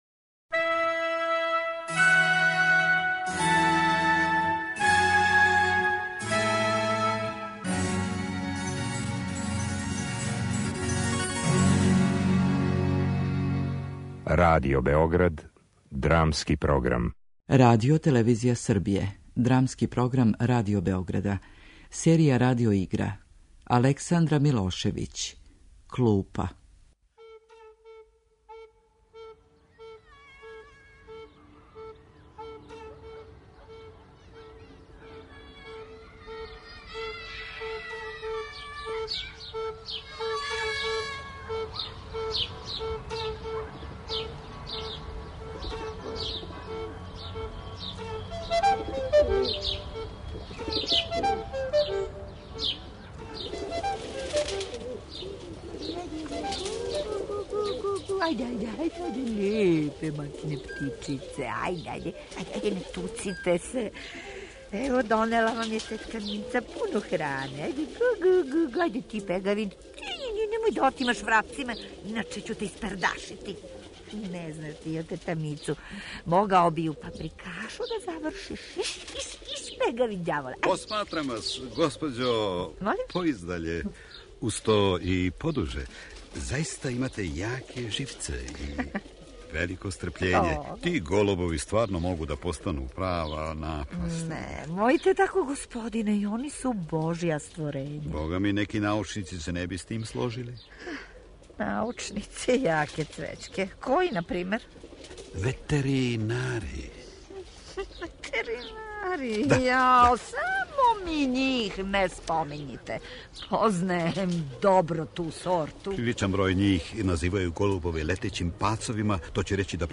Радио игра: Клупа
RADIO IGRA.mp3